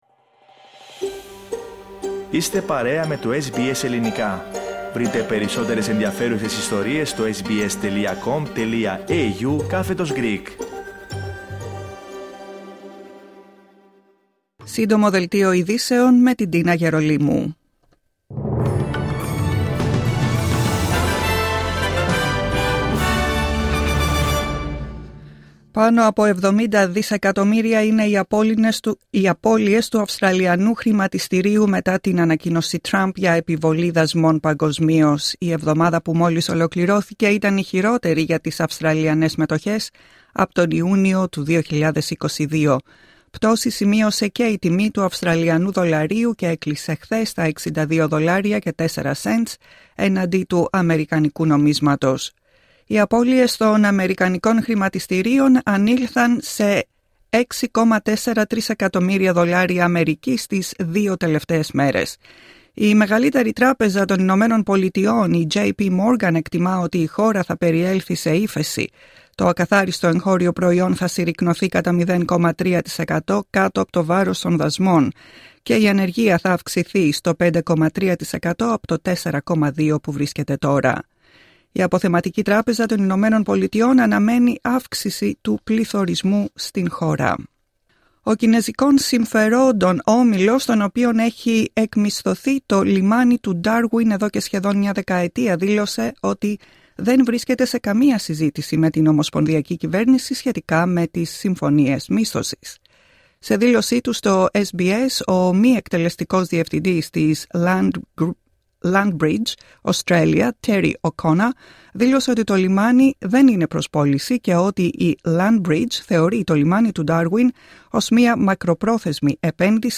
Δελτίο ειδήσεων Σάββατο 5 Απριλίου 2025